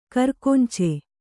♪ karkonce